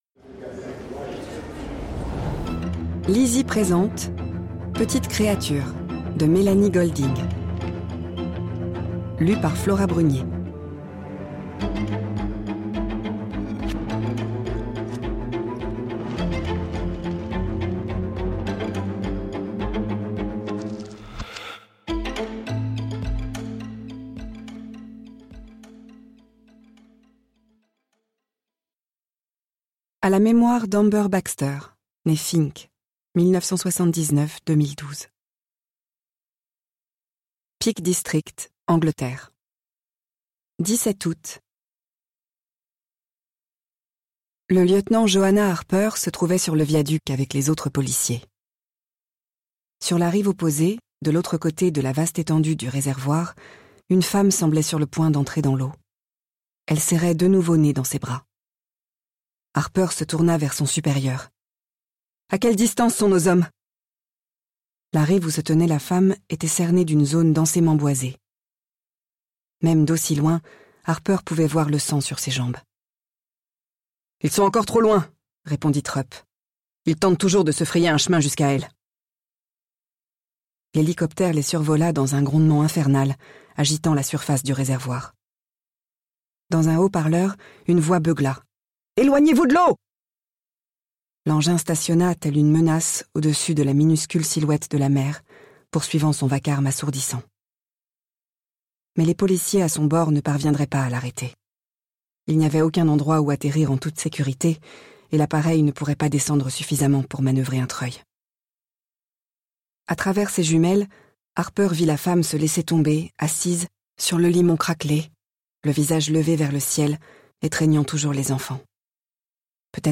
Extrait gratuit - Petites Créatures de Melanie GOLDING